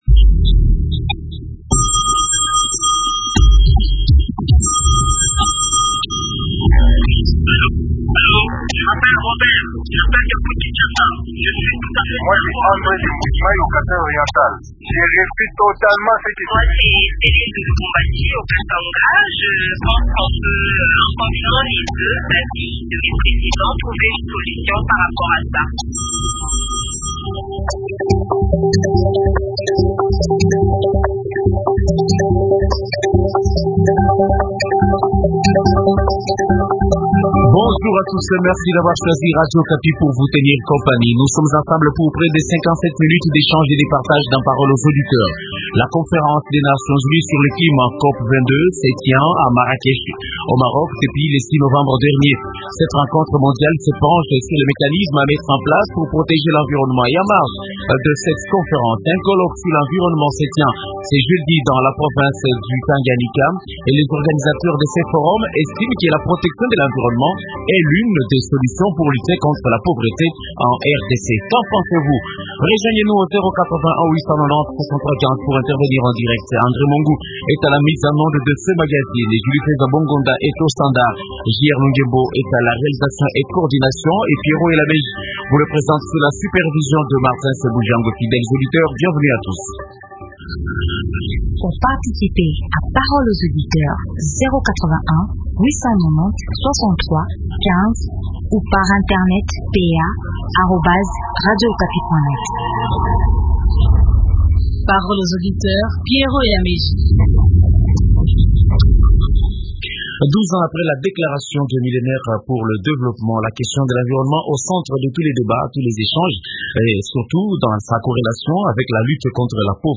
Invité: John Banza, Ministre provincial du Plan et environnement dans la province du Tanganyika.